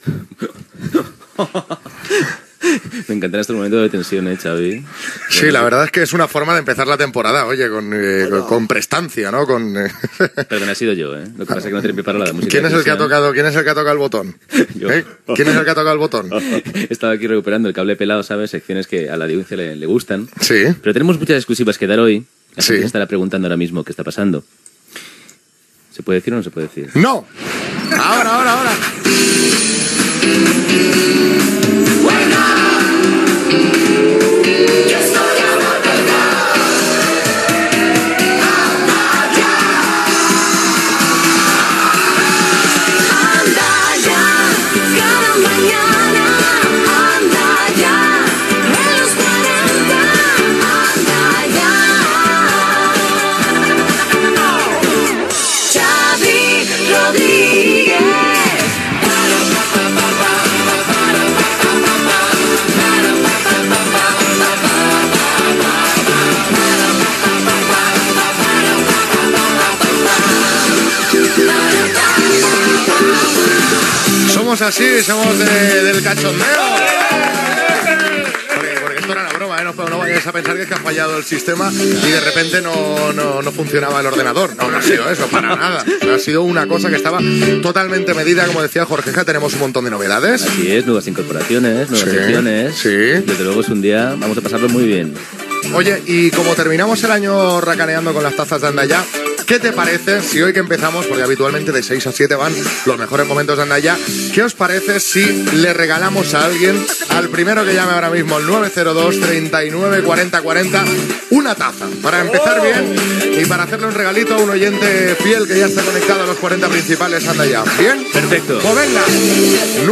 Diàleg inicial, careta del programa, presentació, telèfon de contacte, notícies del dia, esports, trucada telefònica, el número 1 de Los 40 Principales
Entreteniment
FM